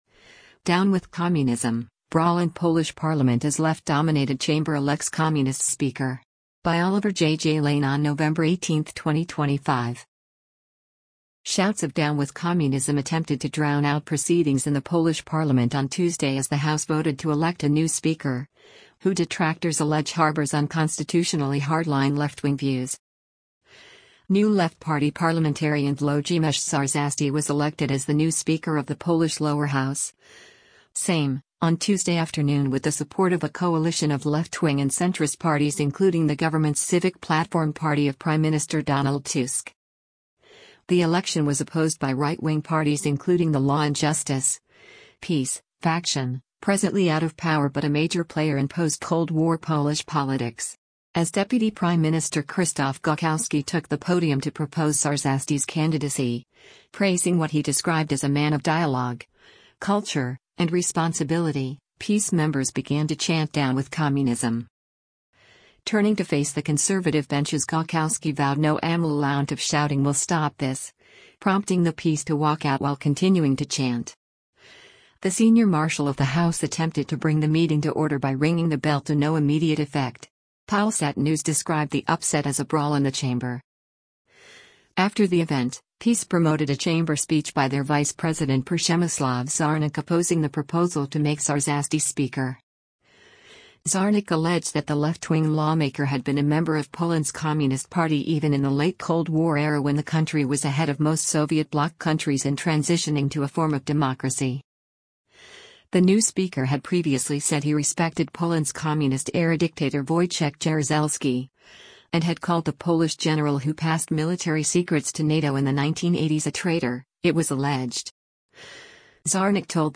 Shouts of “down with communism” attempted to drown out proceedings in the Polish parliament on Tuesday as the house voted to elect a new speaker, who detractors allege harbours unconstitutionally hard-line left-wing views.
As Deputy Prime Minister Krzysztof Gawkowski took the podium to propose Czarzasty’s candidacy, praising what he described as a man of “dialogue, culture, and responsibility”, PiS members began to chant “down with communism”.
The senior marshal of the house attempted to bring the meeting to order by ringing the bell to no immediate effect.